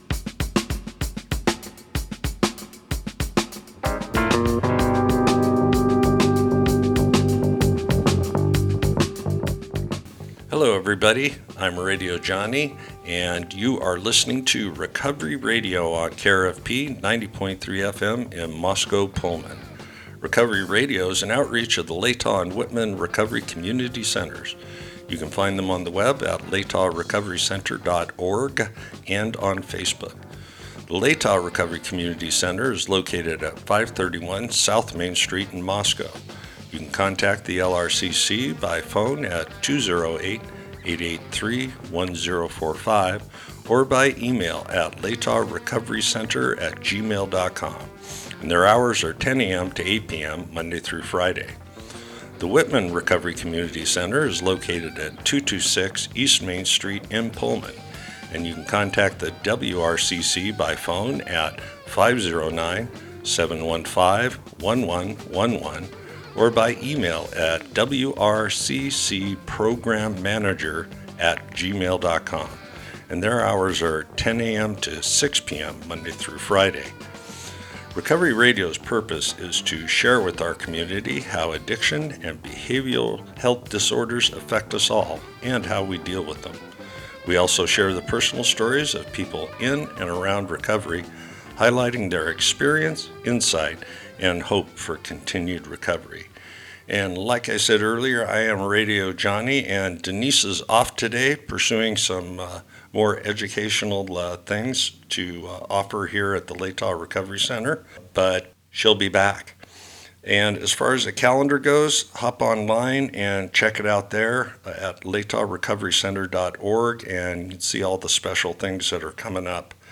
Program Type: Interview